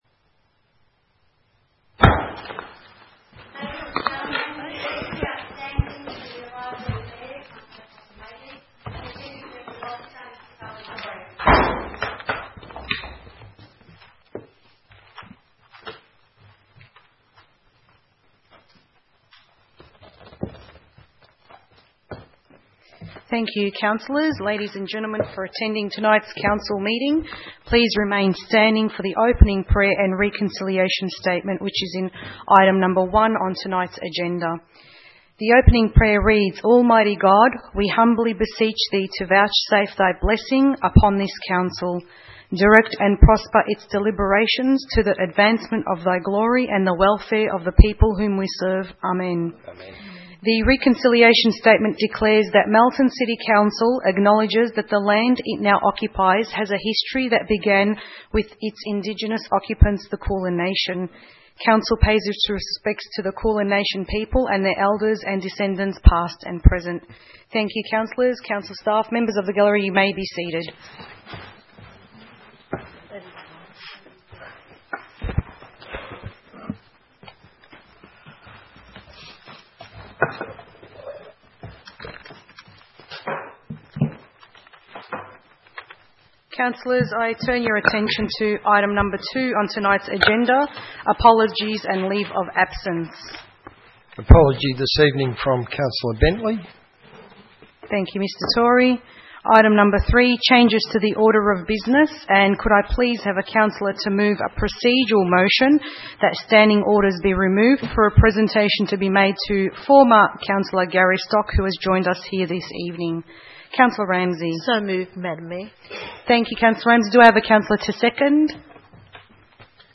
12 September 2016 - Ordinary Council Meeting